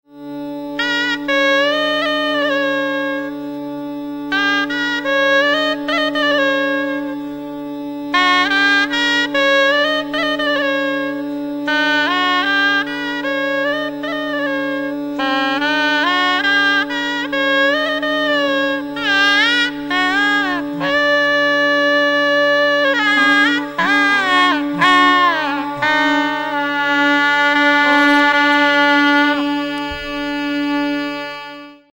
S-r-G-M-D-N-S
A prominent sunset raga, Puriya takes the same six swaras as Marwa and Sohini, reworking them to bring a distinct set of melodic forces. Renditions tend to focus on mandra and madhya saptak, seeking a relatively even balance between ascending and descending phrases – with its character often considered more ‘melodic’ than Marwa (which is comparatively ‘geometric’). Some describe its mood as one of ‘sombre piety’, while others find more playful essences in its strange vibrations, built around a strong GaNi sangati and MMG pakad.